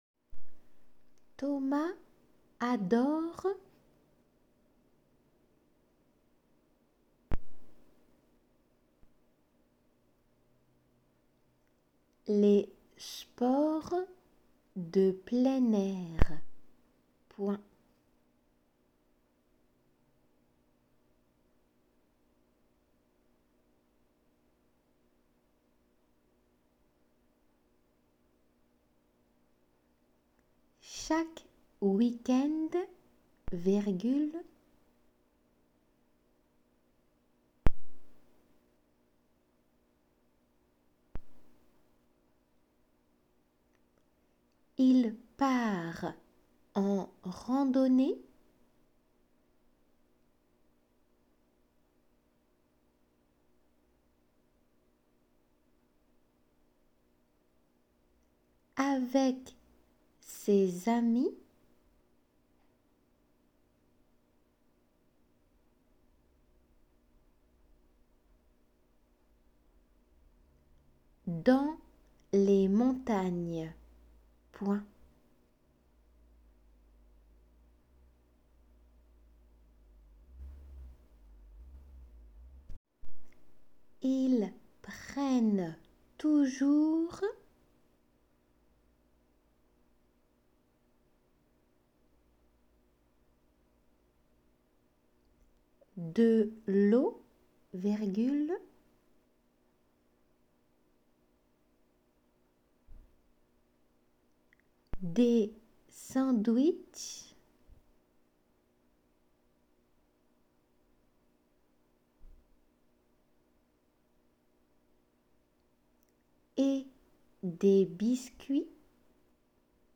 仏検　2級　デイクテ　音声　秋 7
このホームページではデイクテの速さのみ1回読まれています。